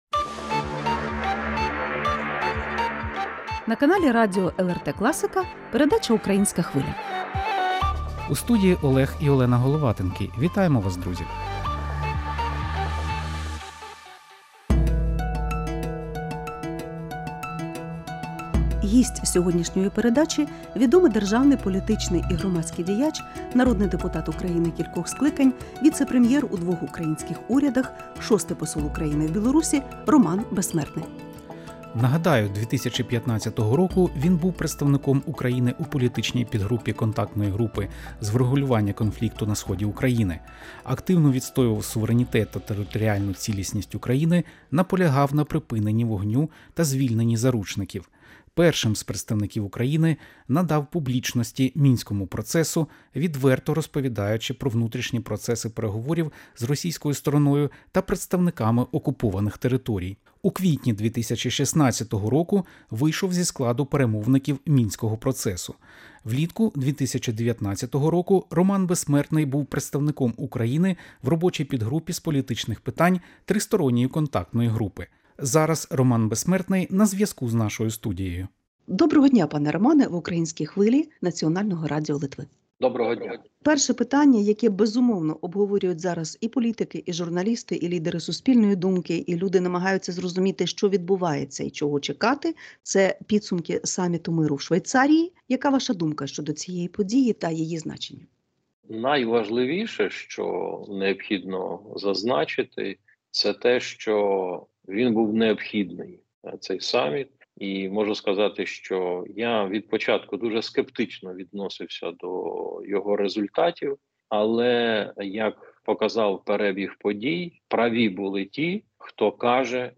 Гість передачі - відомий державний, політичний і громадський діяч, народний депутат України кількох скликань, віцепрем'єр у двох українських урядах, 6-й Посол України в Білорусі Роман Безсмертний.